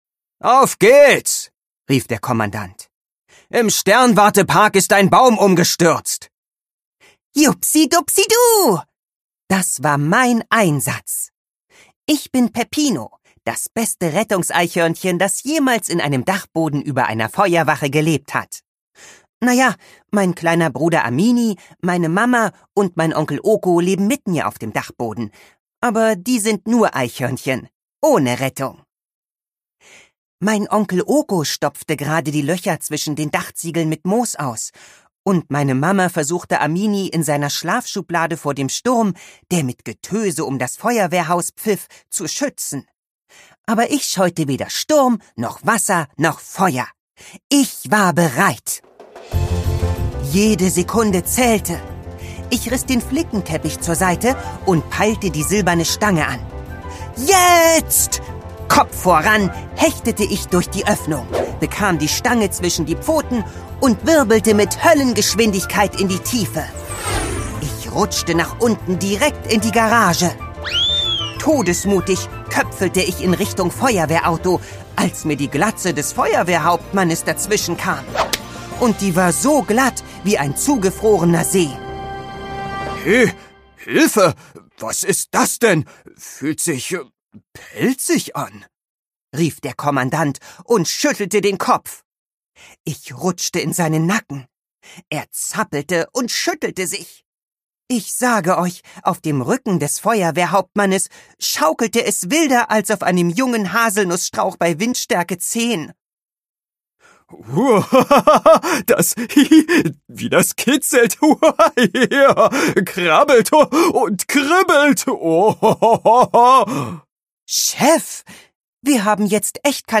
Hörbuch
Gekürzte Lesung, Inszenierte Lesung